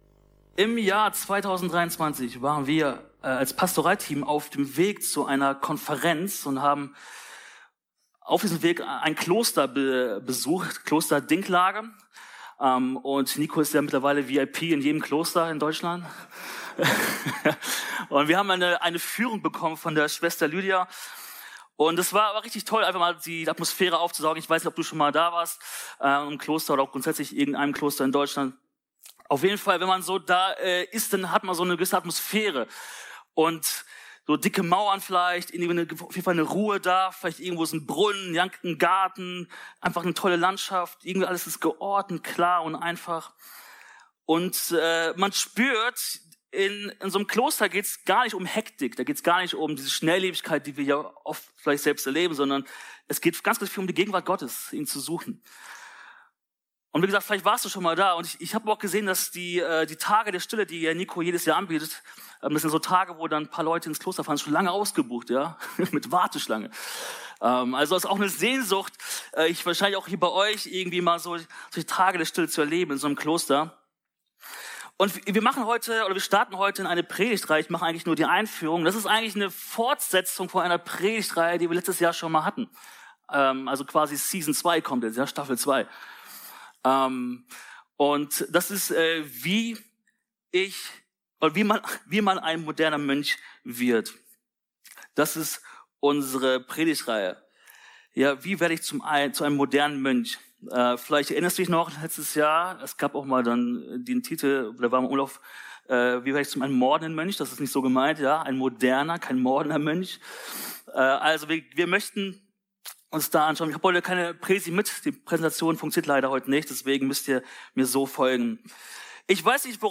24 - Einzelne Predigten